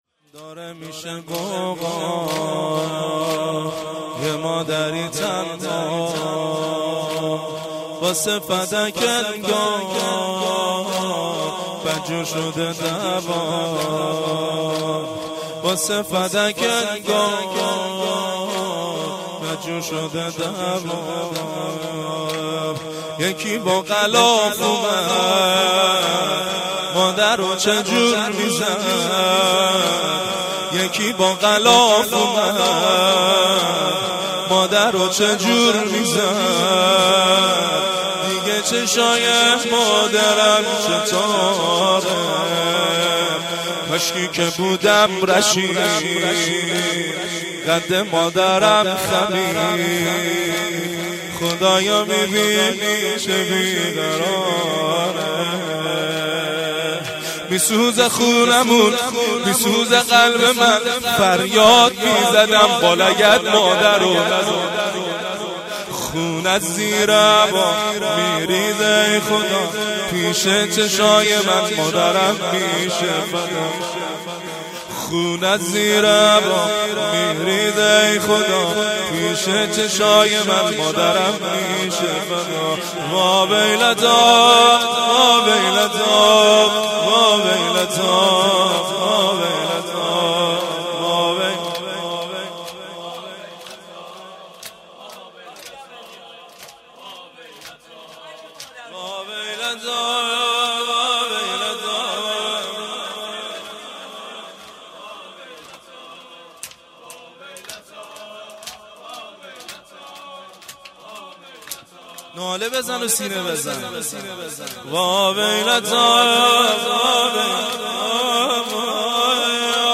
استقبال از فاطمیه